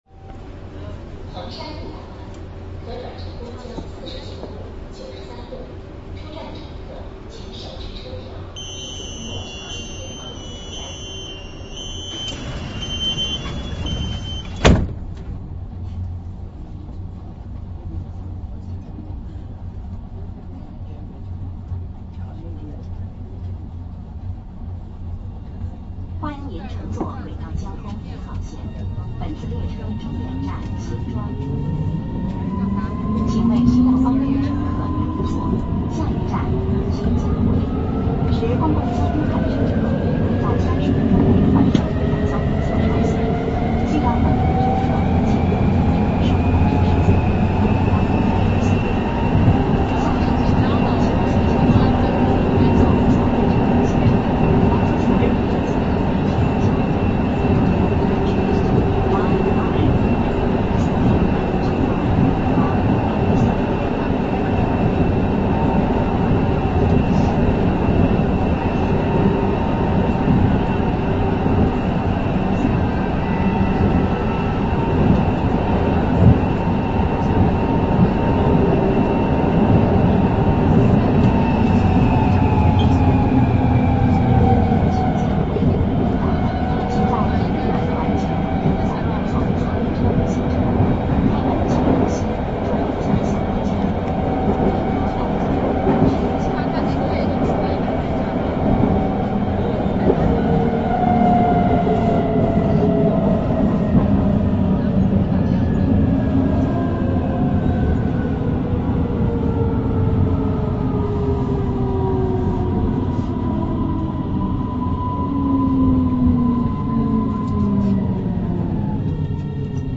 Tc-M-M-M-M-Tc（4M2T）の6両編成。
扉閉時にはドアチャイムが鳴る。
1号線DC01型電車走行音（上海馬戯城→ｼ文水路）